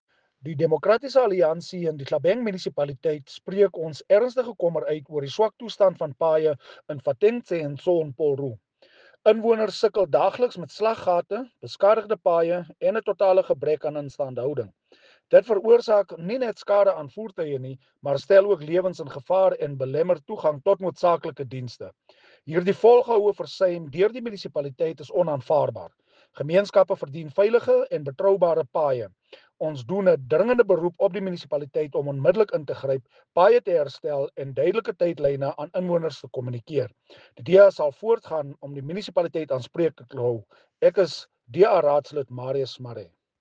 Afrikaans soundbite by Cllr Marius Marais.